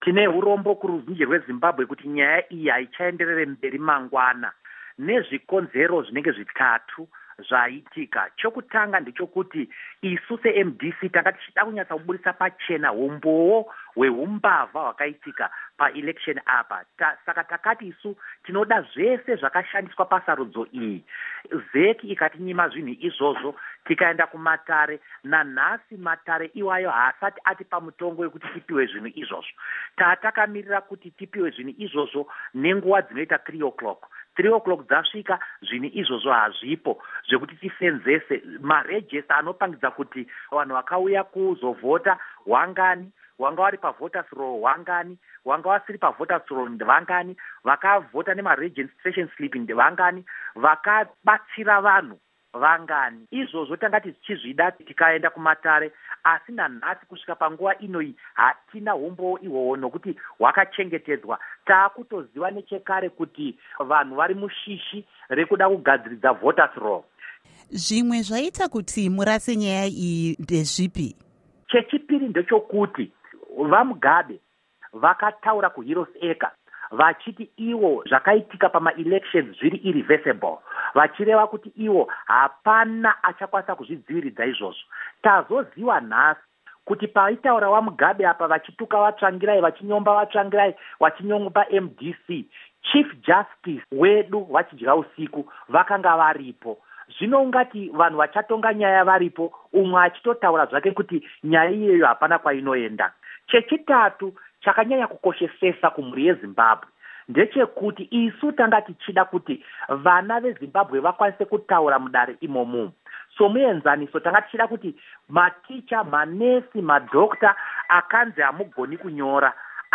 Hurukuro NaVa Douglas Mwonzora